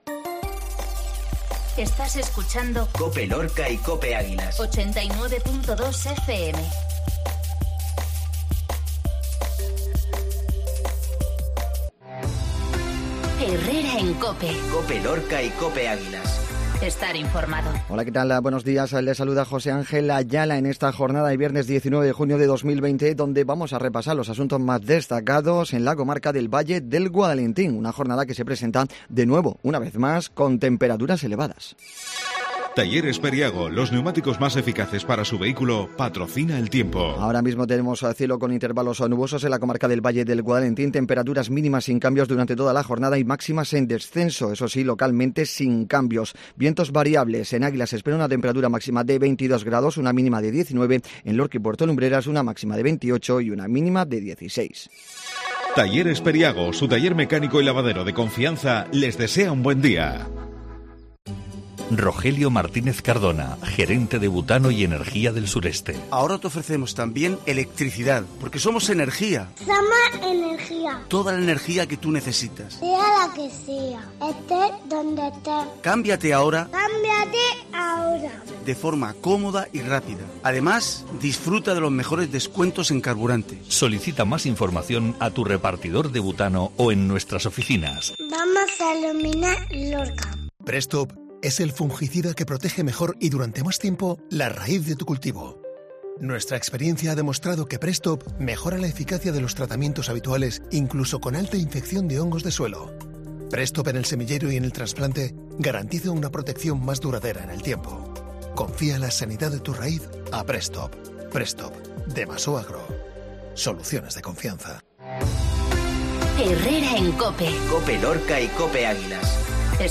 INFORMATIVO MATINAL VIERNES